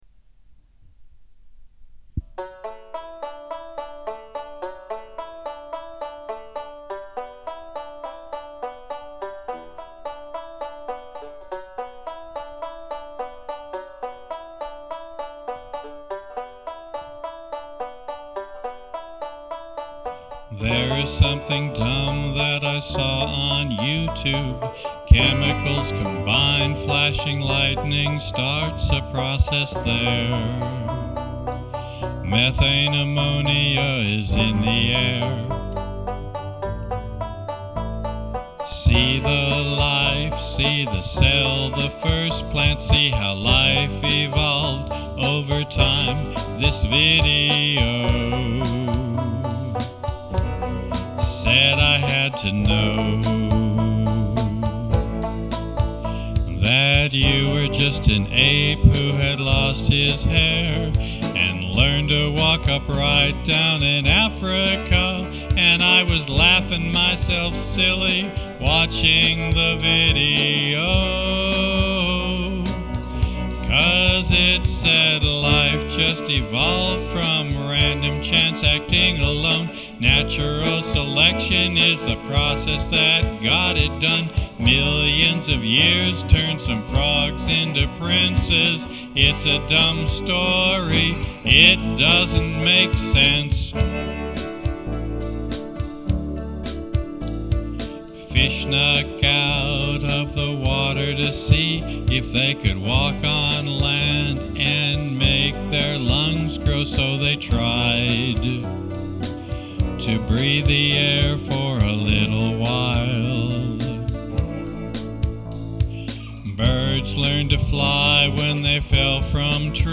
1 Lead guitar, rhythm guitar, bass guitar
synth guitar
with Alesis SR 18 drum machine.